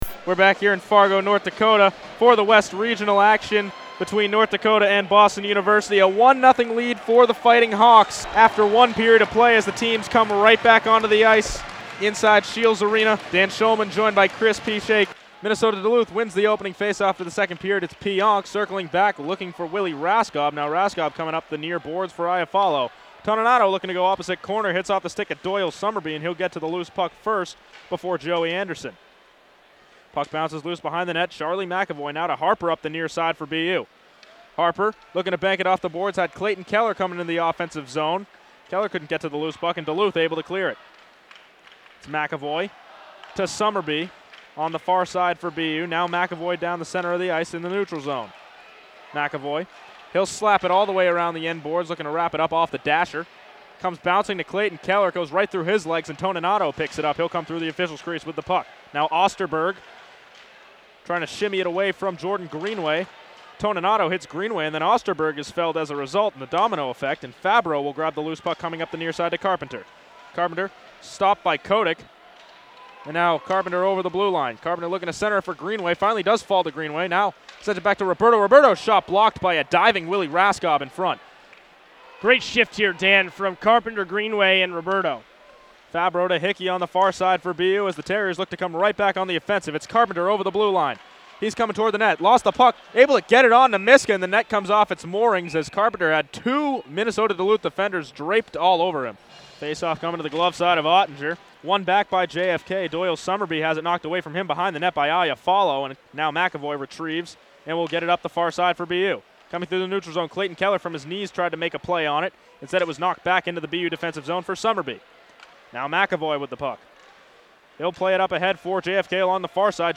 2017 Hockey Broadcasts
2017 NCAA D-I Men’s Ice Hockey West Regional Final: Boston University vs. Minnesota-Duluth – March 25, 2017 – Scheels Arena, Fargo, North Dakota
Overtime (color) Third Period (color) Second Period (Play-by-play)